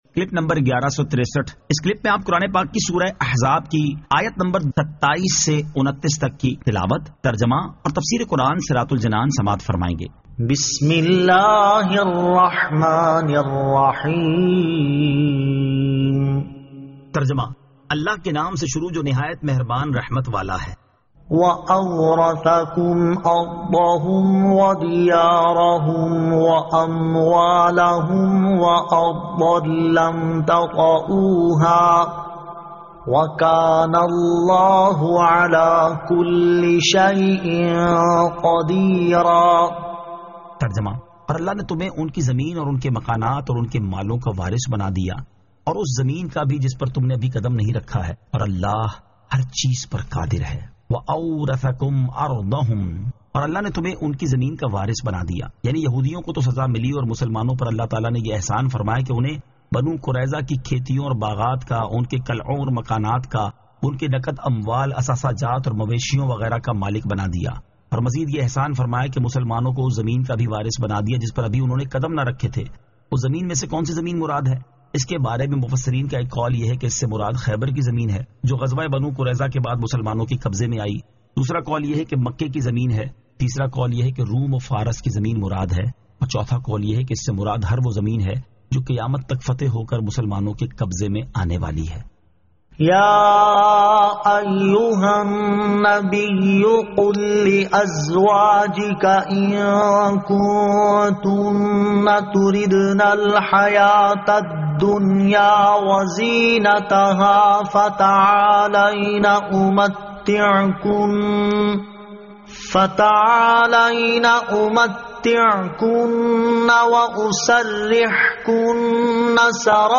Surah Al-Ahzab 27 To 29 Tilawat , Tarjama , Tafseer